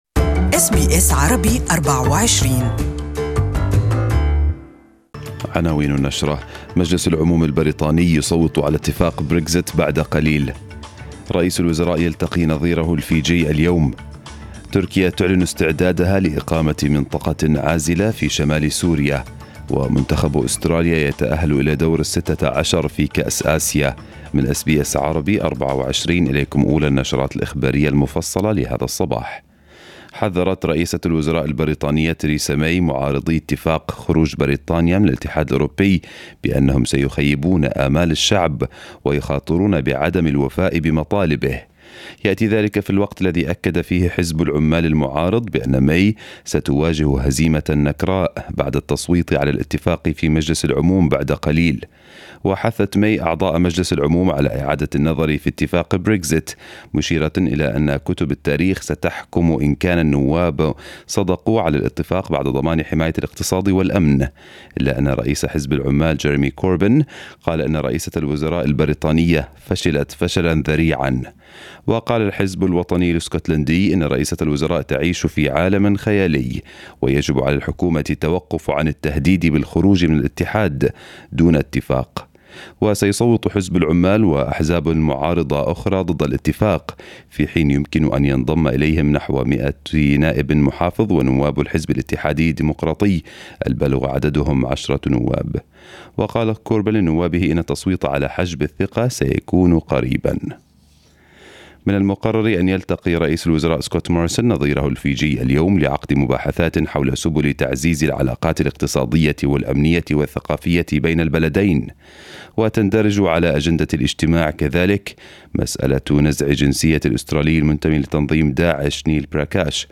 News bulletin in Arabic for the day